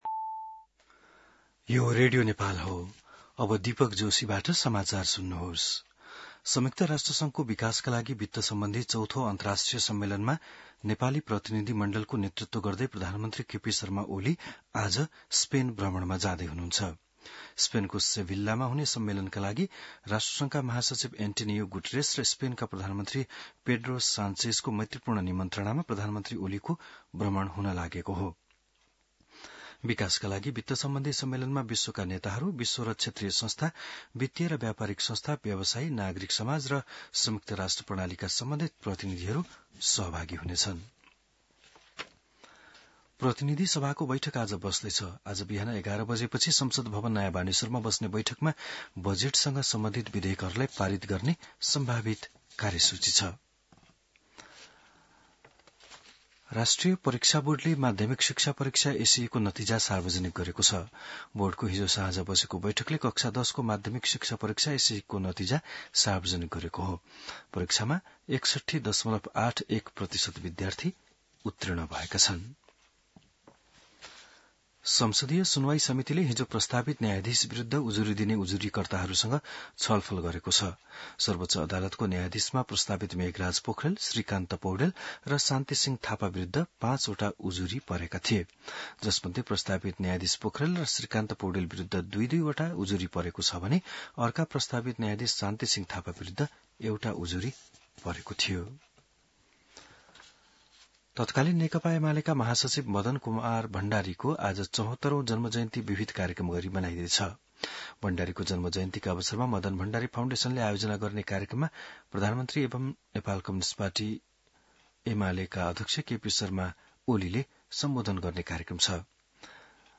बिहान १० बजेको नेपाली समाचार : १४ असार , २०८२